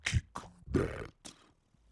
• demonic techno voice "kick that 2".wav
Changing the pitch and transient for a studio recorded voice (recorded with Steinberg ST66), to sound demonic/robotic.